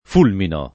fulminare v.; fulmino [ f 2 lmino ]